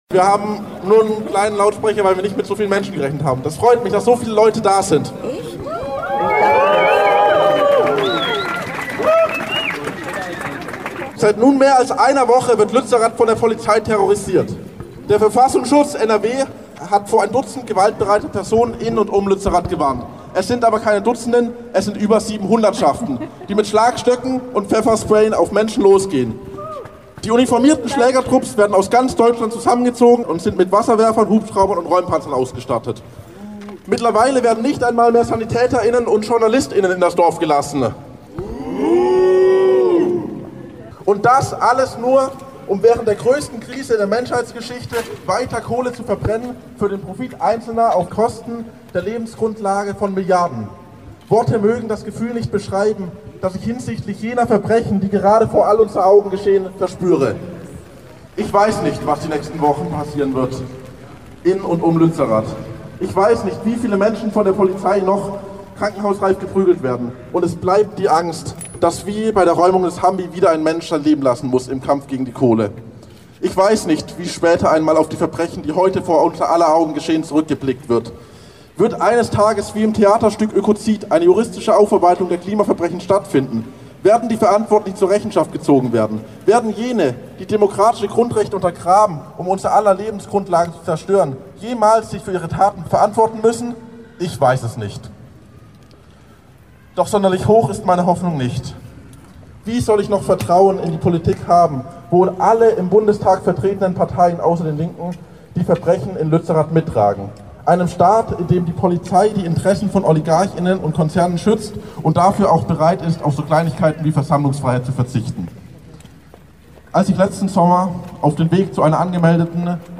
Hier hört Ihr Interviews mit Teilnehmer*innen und die Reden auf dem Marktplatz, vor dem Parteibüro der CDU und vor dem der Grünen.
89805_Demo-Luetzi-TUE-Reden.mp3